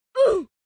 roblox-oof.ogg